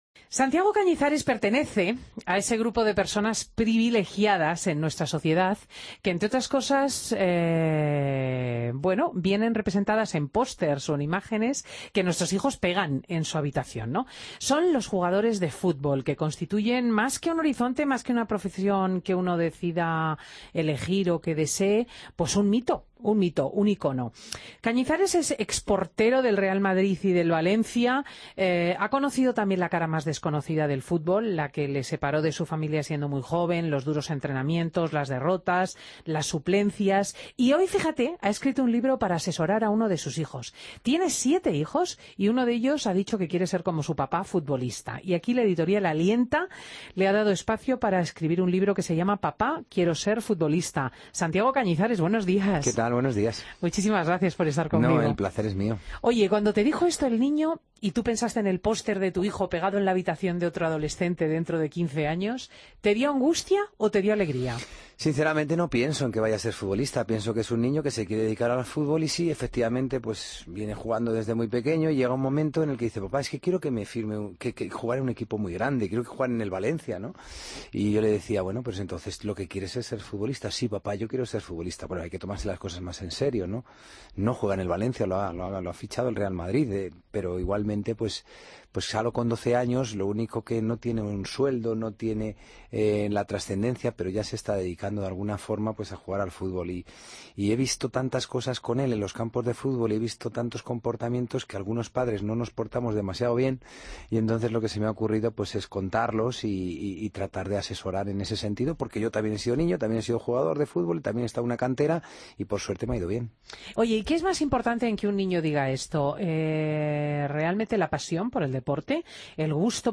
Entrevista a Santiago Cañizares en Fin de Semana COPE